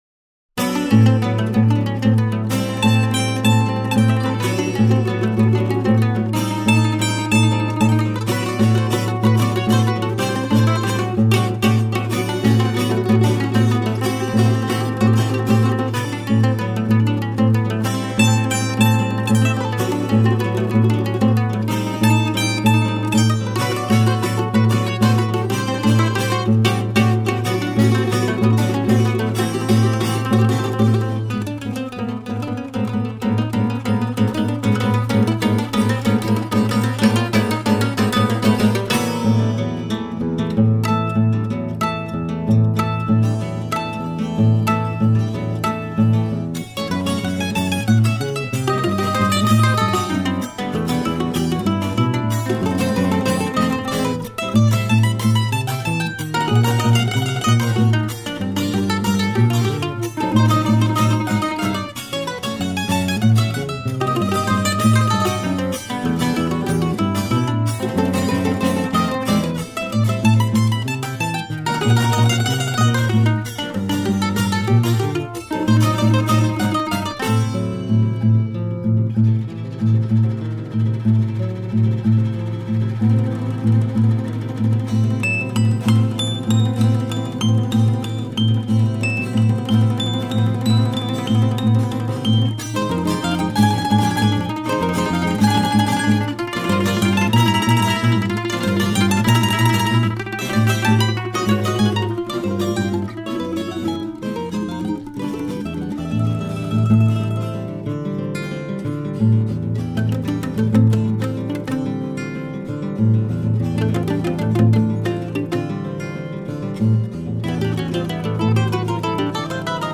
Caña- Bambuco
Cuarteto Instrumental
Colombiana